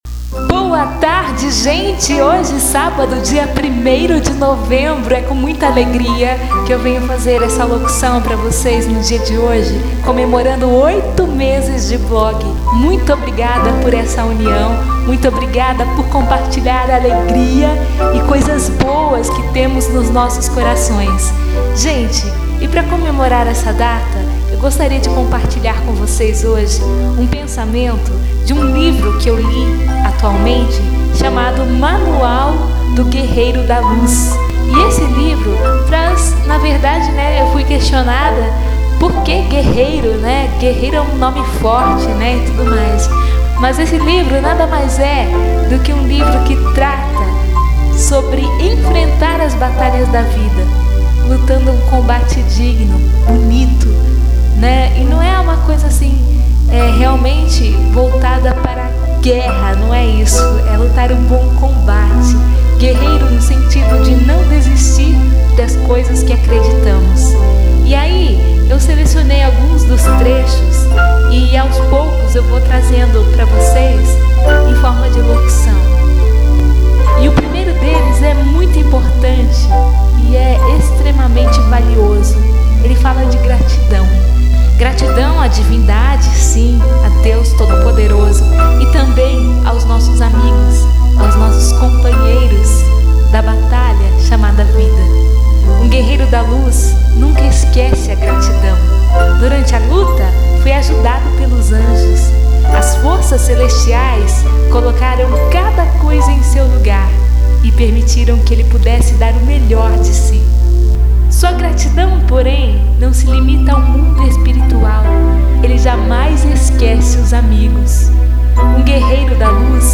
Me desculpem pela qualidade do áudio, mas o microfone estava mal plugado, no entanto, eu o mantive porque a maneira com que  falei foi tão natural e verdadeira que eu preferi deixá-lo assim.